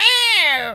bird_vulture_hurt_01.wav